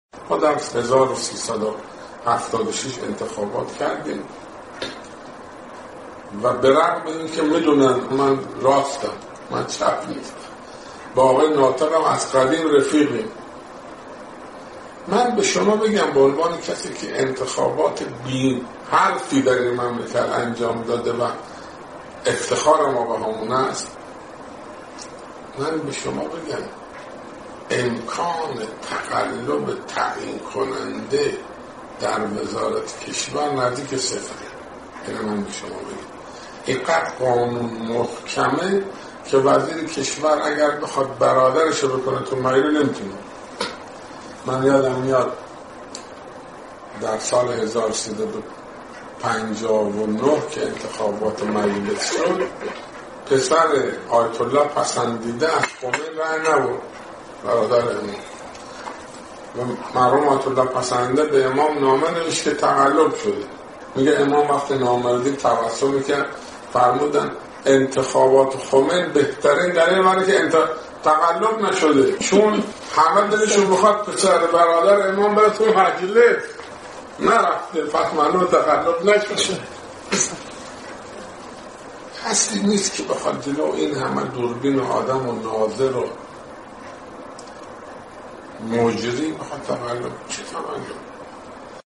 گفتگو با علی محمد بشارتی وزیر سابق کشور